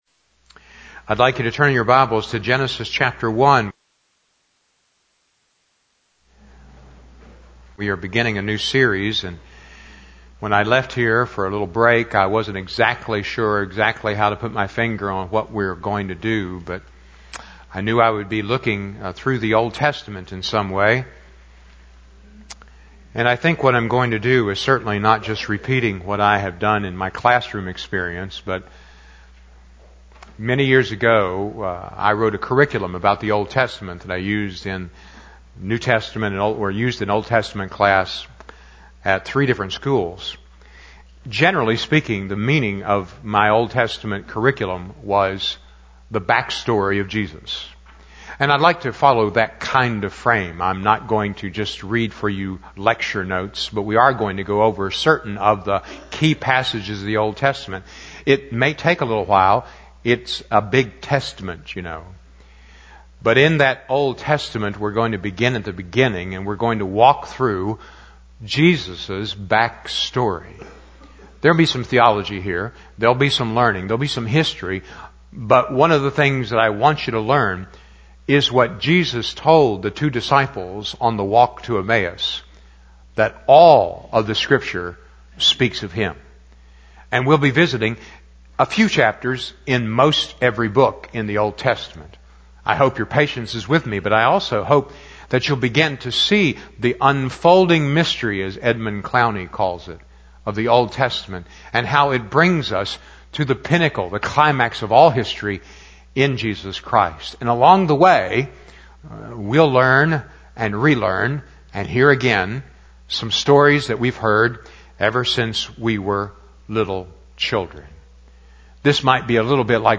Passage: Genesis 1 Service Type: Sunday Evening